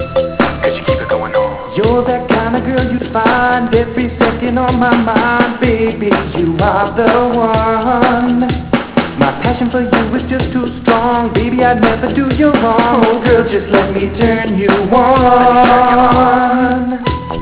STYLE: Dance with Rap
With a strong groove, bouncing bass, and smooth vocals